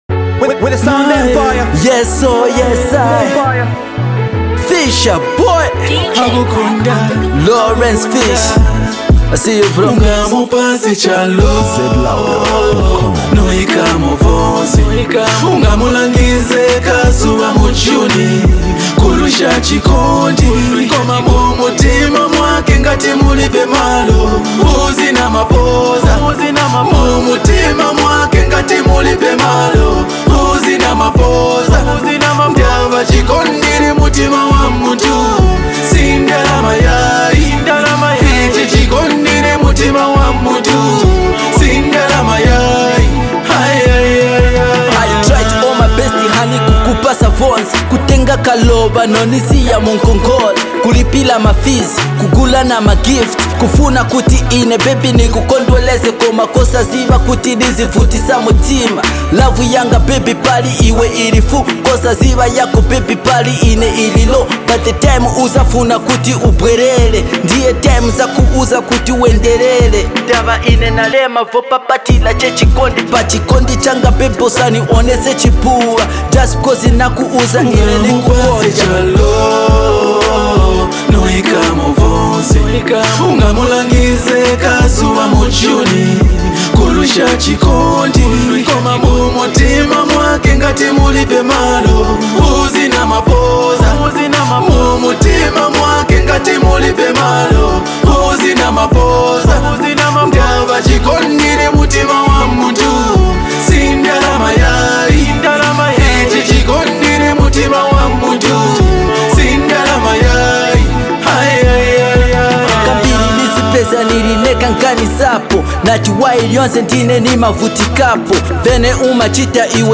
Heart touching Sound